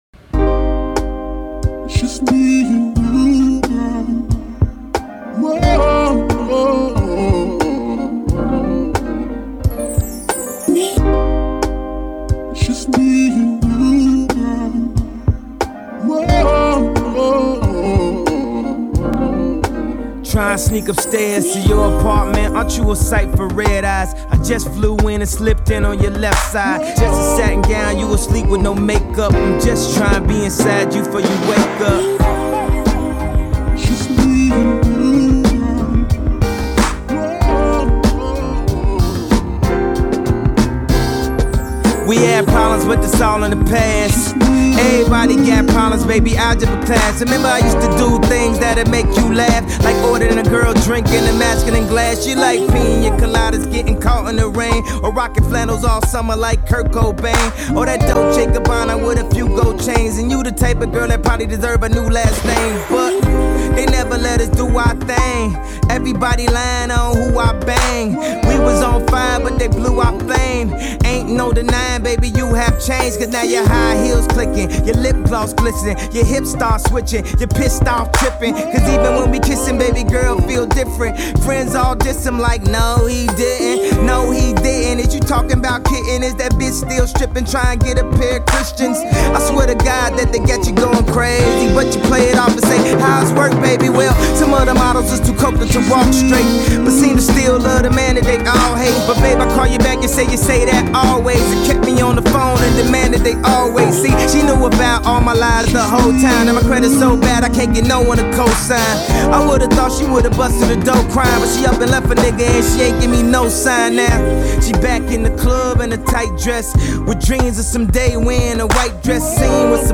Estilo: Hip-Hop